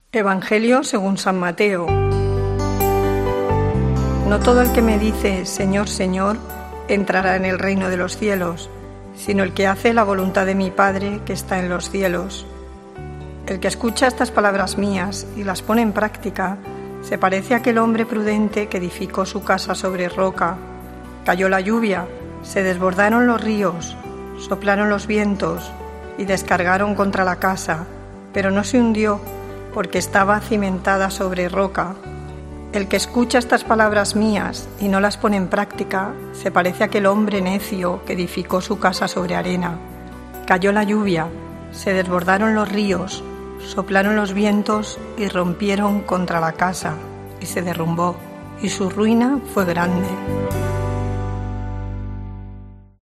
Lectura del santo evangelio según san Mateo 7, 21. 24-27